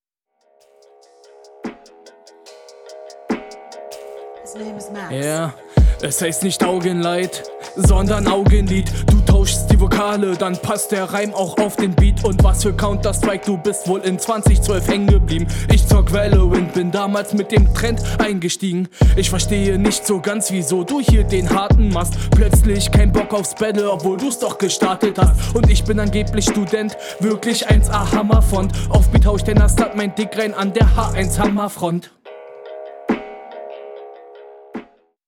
Flow: manchmal off beat Text: geht so, nichts krasses aber auch nicht komplett beschissen Soundqualität: …